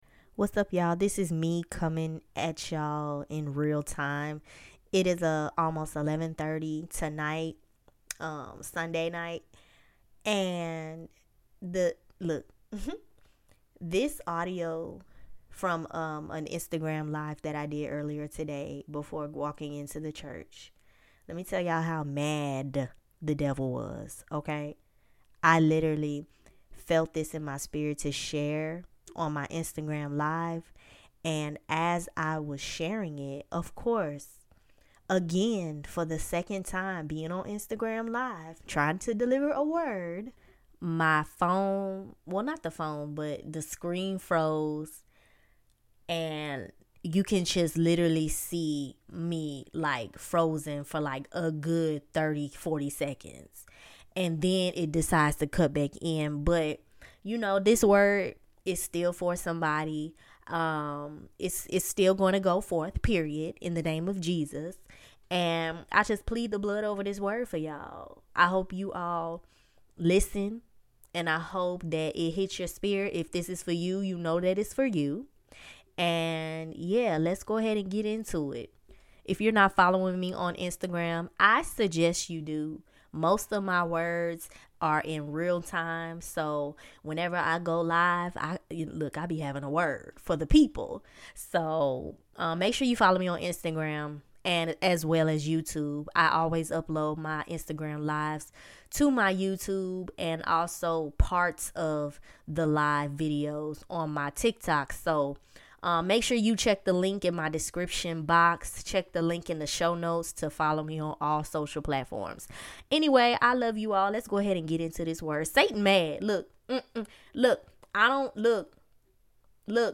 This prophetic word was interrupted during an IG LIVE this morning right before walking into church so this WORD is DEFINITELY for somebody!!